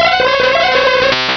Cri d'Hypnomade dans Pokémon Rubis et Saphir.